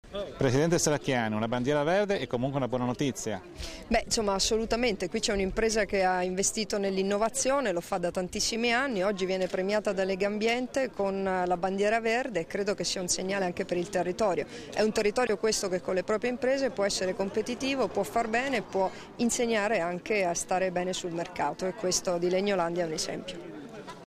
Dichiarazioni di Debora Serracchiani (Formato MP3) [386KB]
rilasciate alla consegna della "Bandiera Verde 2013" di Legambiente all'Azienda Legnolandia, a Forni di Sopra il 28 settembre 2013